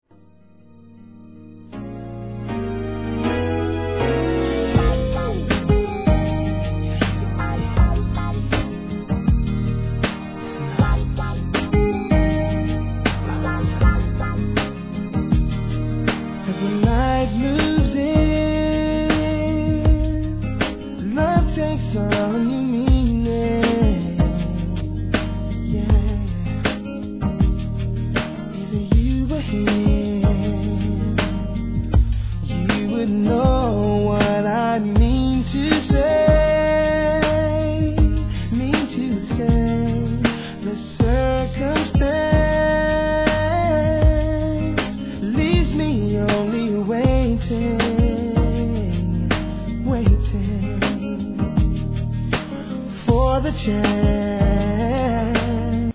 HIP HOP/R&B
激メロ〜トラックで歌うSWEET VOICEに切なくなるほど!